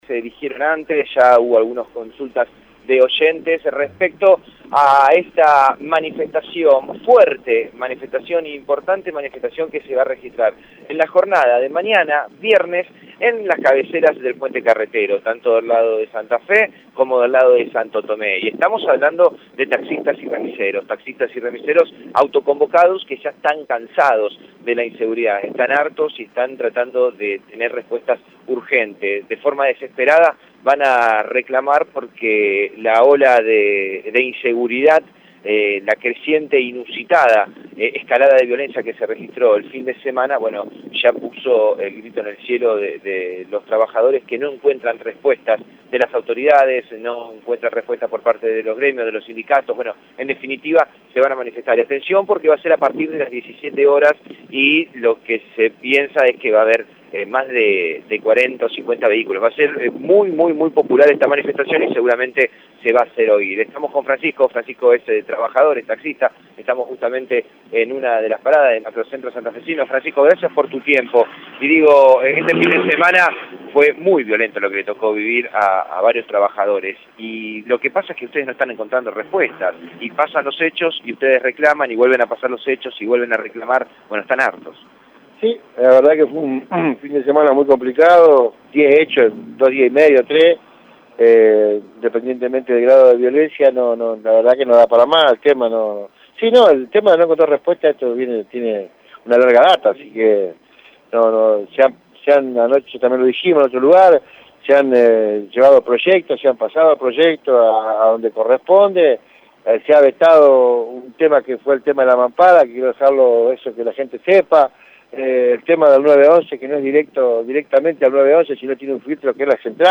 Choferes dijeron en Radio EME que fue un fin de semana complicado, «hubo 10 hechos de inseguridad en dos días.